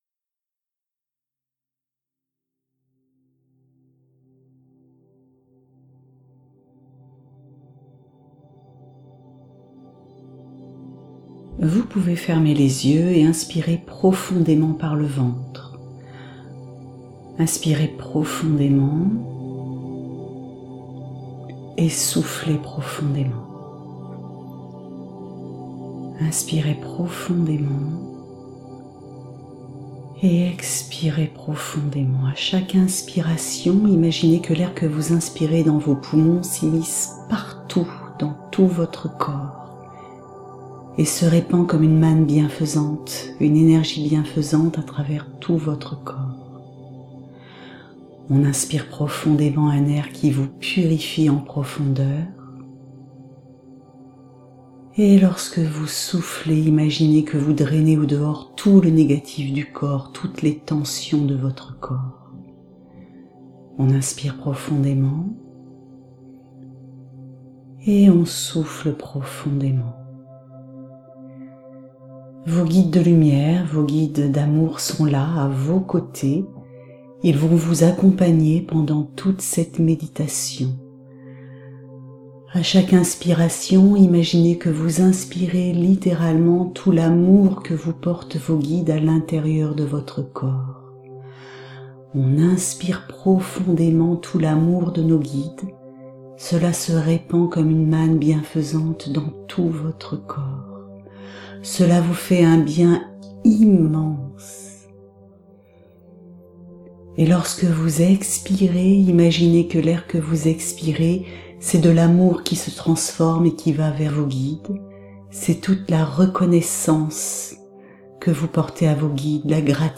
Genre : Méditation.
meditation-regenerante.mp3